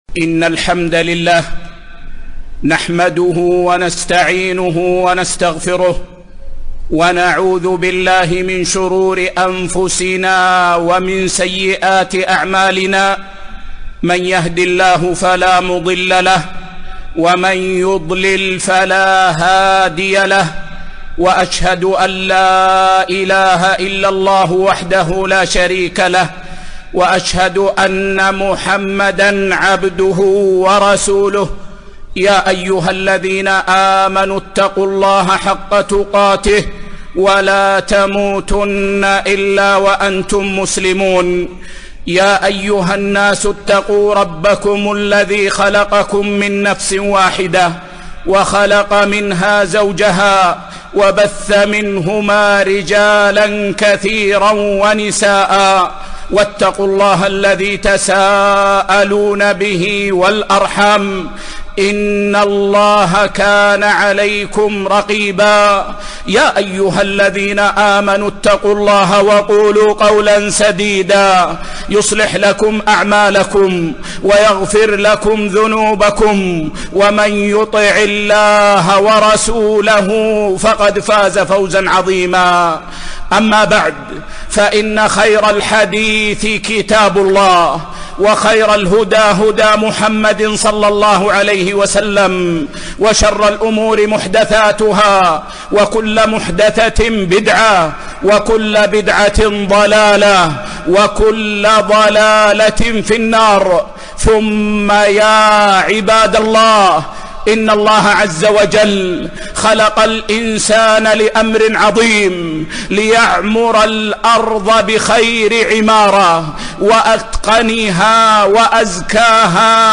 خطبة - أصول الحياة الطيبة